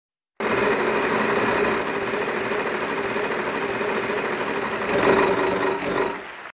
Play Wheel Of Fortune Wheel Spin - SoundBoardGuy
Play, download and share Wheel Of Fortune Wheel Spin original sound button!!!!
wheel-of-fortune-wheel-spin.mp3